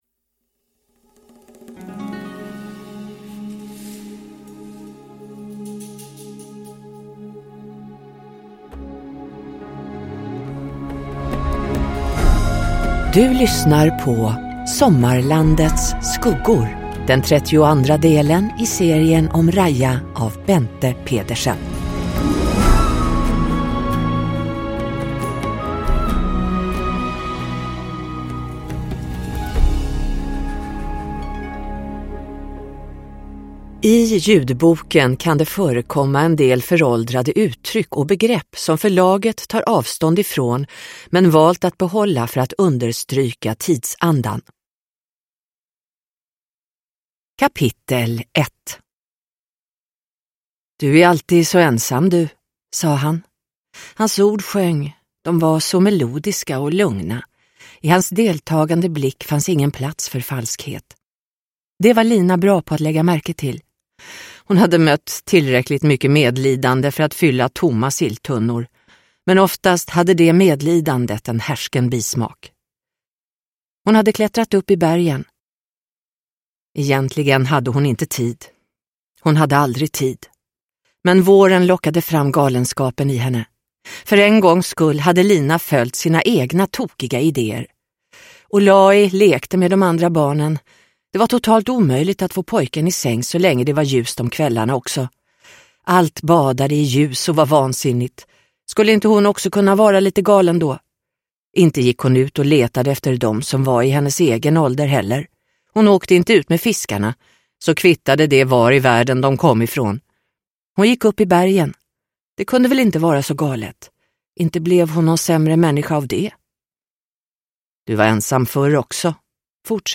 Sommarlandets skuggor – Ljudbok – Laddas ner